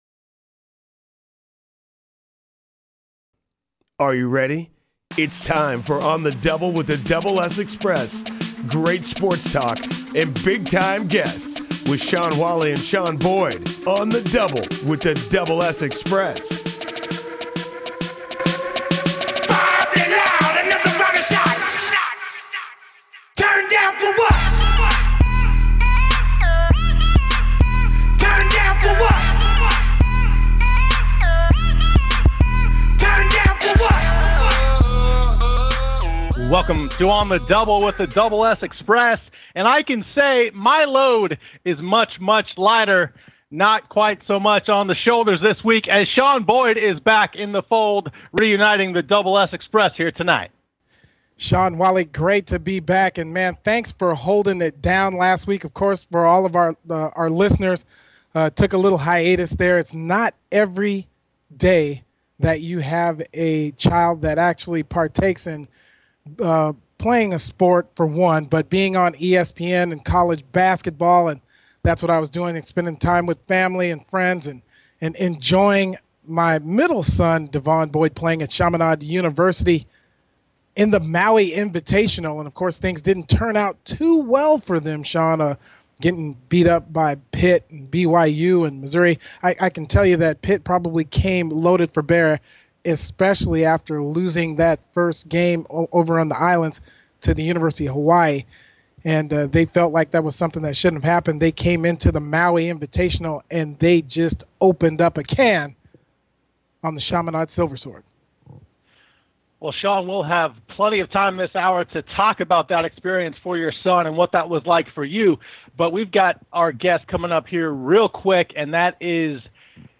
Talk Show Episode
sports talk show